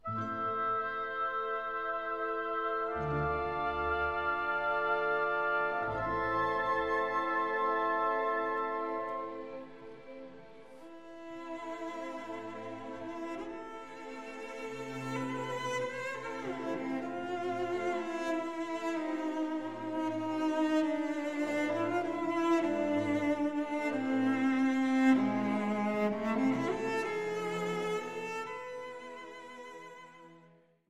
Lebhaft, nicht zu schnell